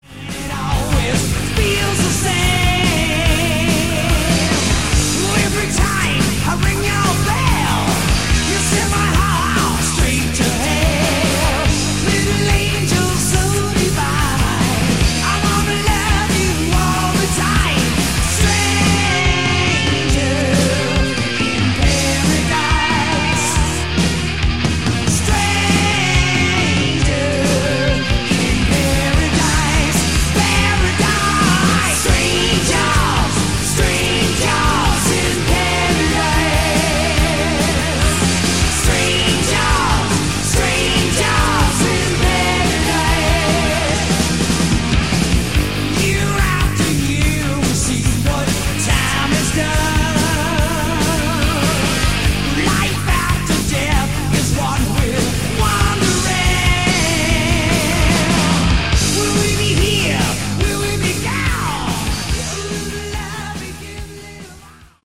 Category: Hard Rock
lead guitar, backing vocals
lead vocals
bass
drums